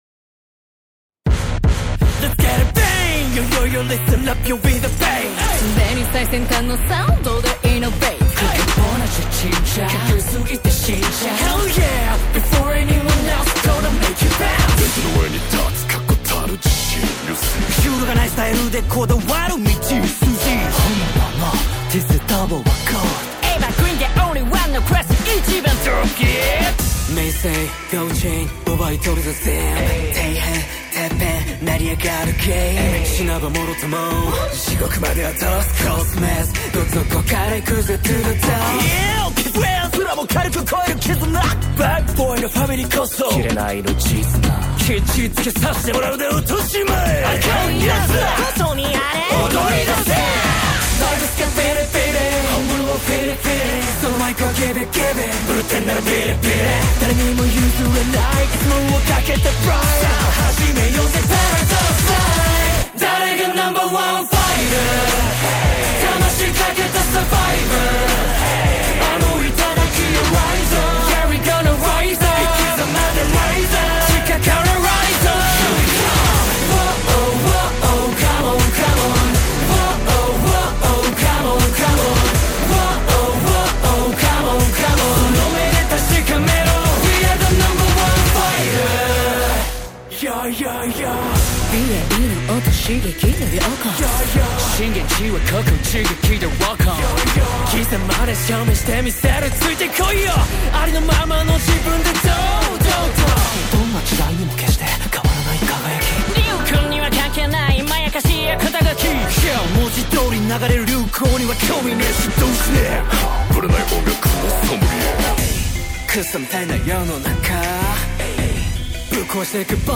اینم ویدیو و لینک اهنگ اوپنینگ: